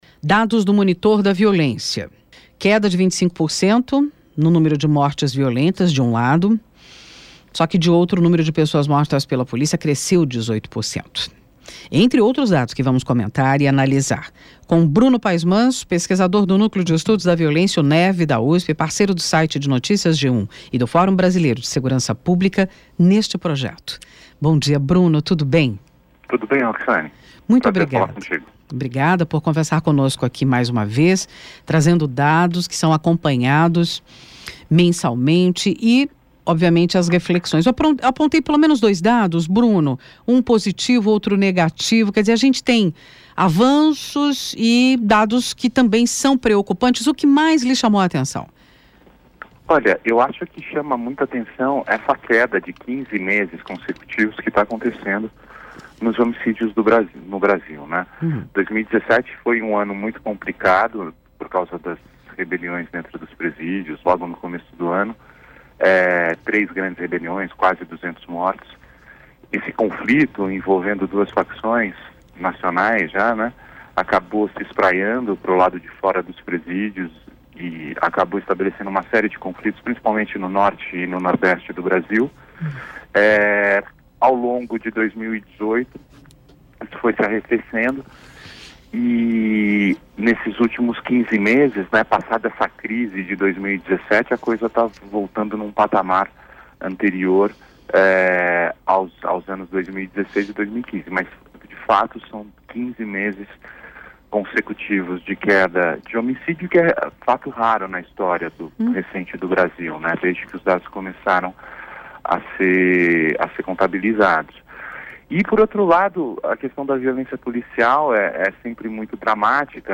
O Jornal da USP no Ar conversou com o pesquisador sobre o cenário atual da violência no País.